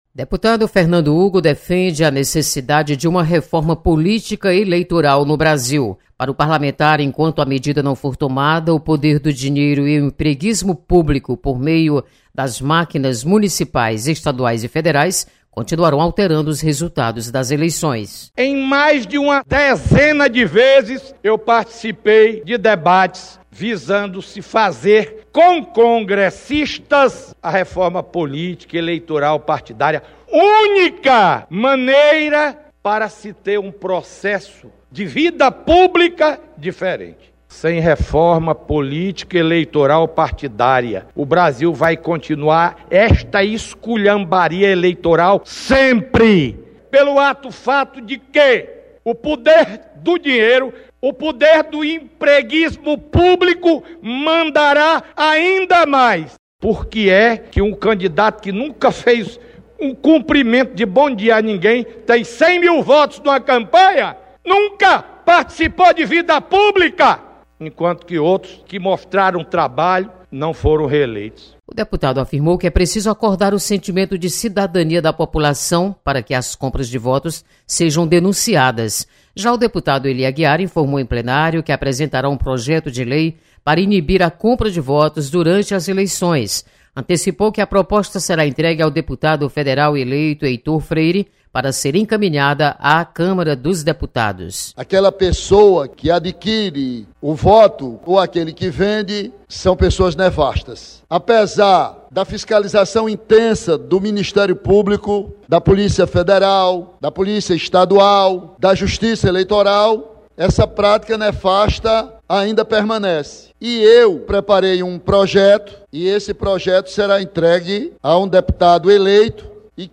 Deputado Fernando Hugo volta a defender uma reforma política e eleitoral no Brasil. Repórter